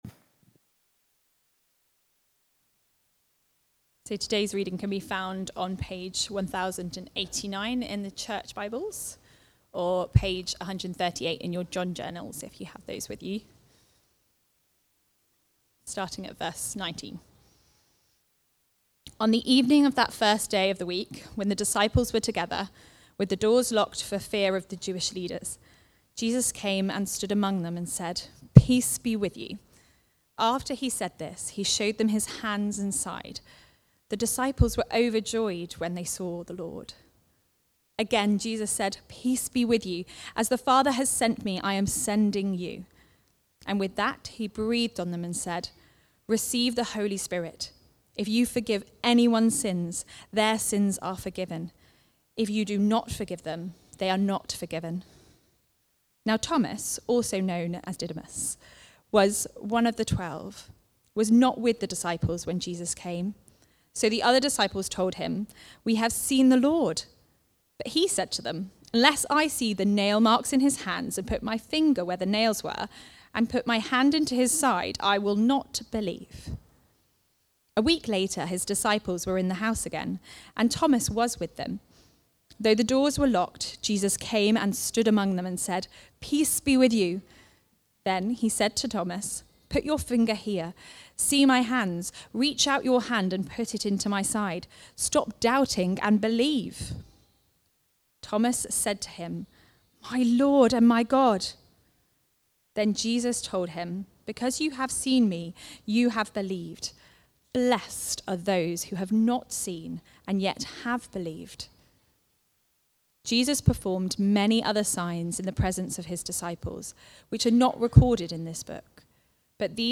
Preaching
The Messengers (John 20:19-31) from the series Life From Death. Recorded at Woodstock Road Baptist Church on 19 April 2026.